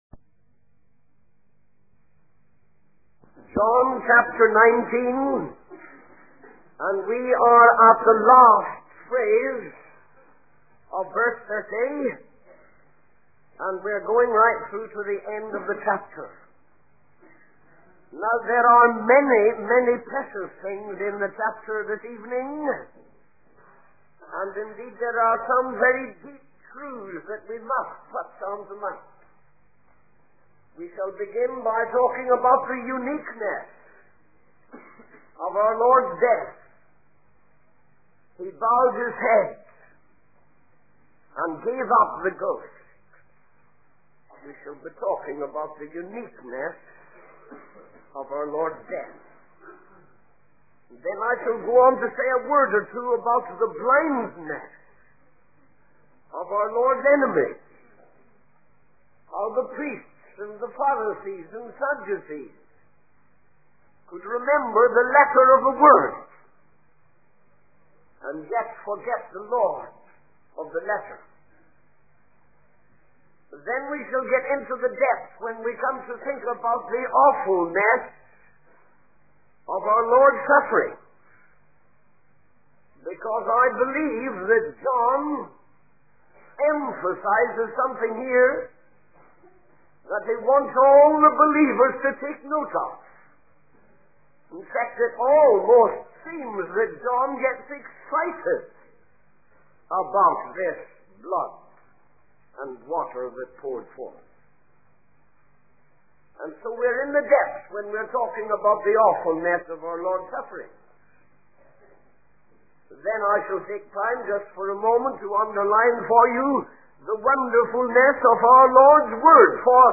In this sermon, the preacher discusses the crucifixion of Jesus Christ. He describes how four men were in charge of the crucifixion, with two starting on one side and two on the other.